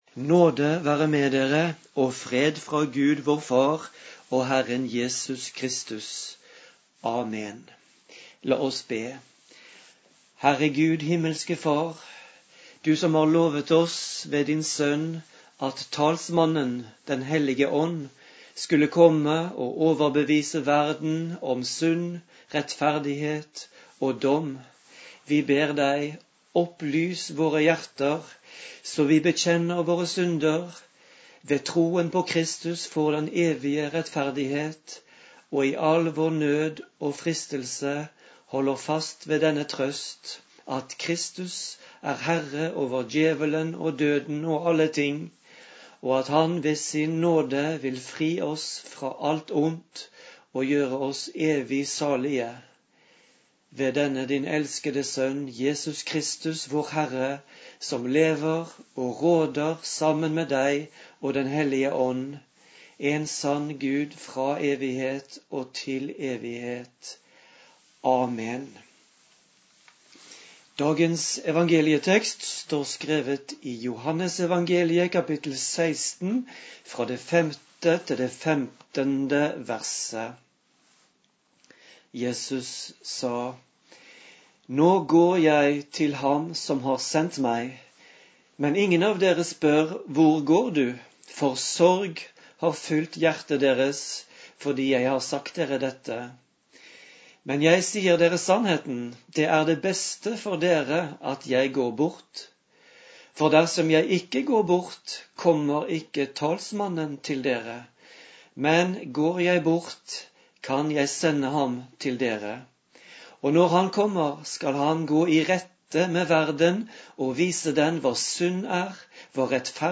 Preken på 4. søndag etter påske
Talsmannen-Preken.mp3